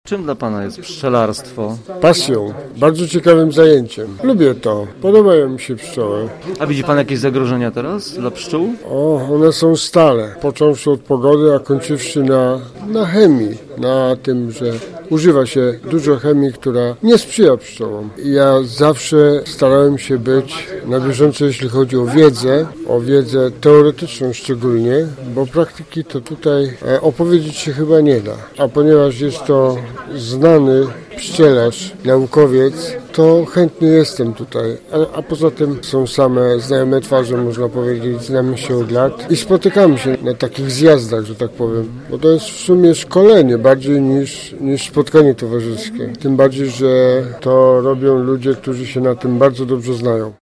Blisko 200 pszczelarzy z całego województwa lubuskiego spotkało się w auli AJP w Gorzowie na I Konferencji Pszczelarskiej.